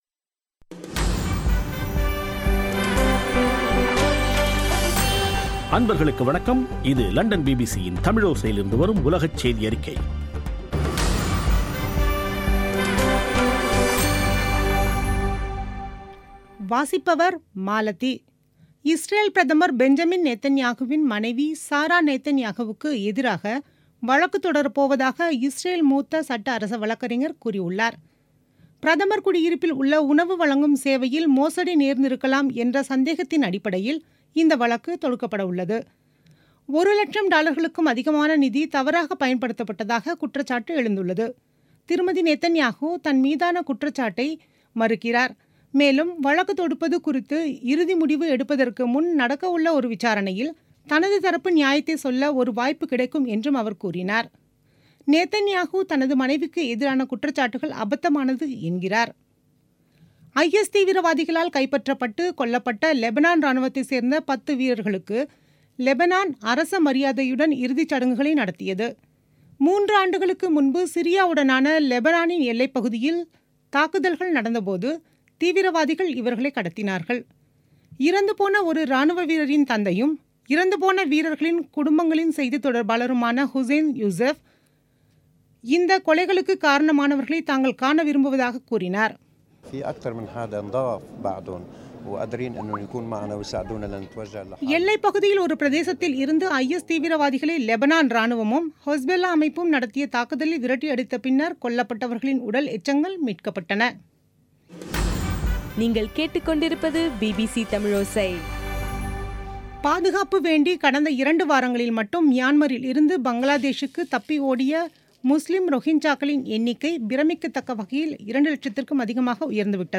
இன்றைய (08.09.2017) பிபிசி தமிழோசை செய்தியறிக்கை